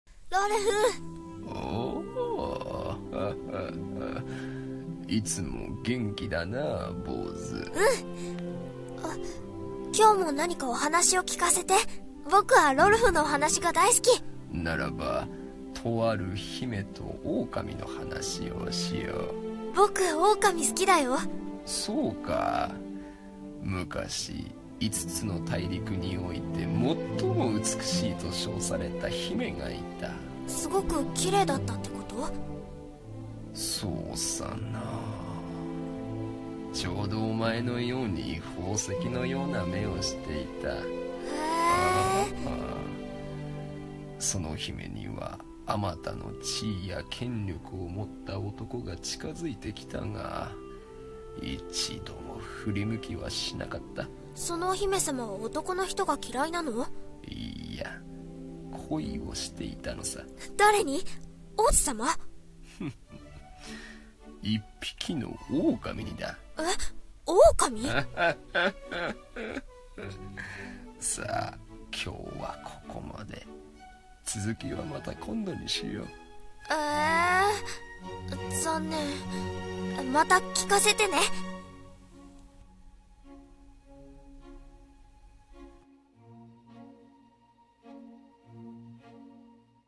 声劇〜老人と少年〜コラボ用(掛け合い声面接)